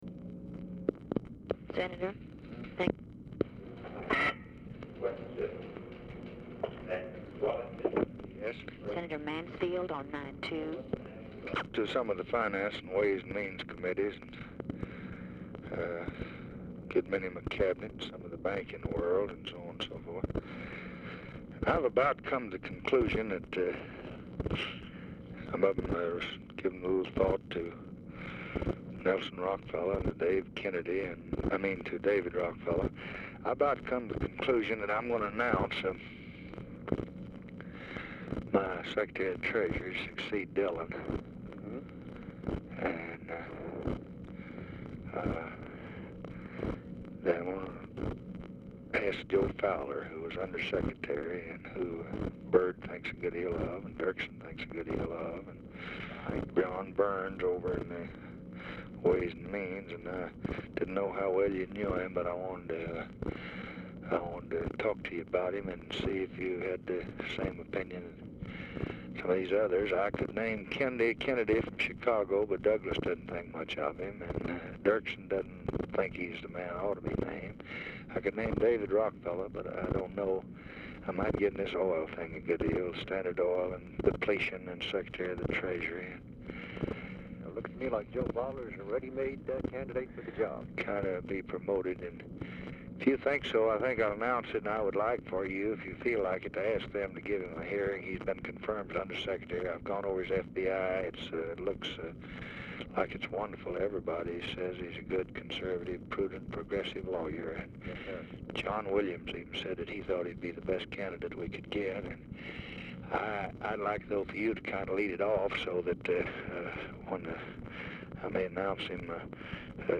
Telephone conversation # 7088, sound recording, LBJ and MIKE MANSFIELD, 3/18/1965, 4:00PM
Oval Office or unknown location
RECORDING STARTS AFTER CONVERSATION HAS BEGUN
Telephone conversation
Dictation belt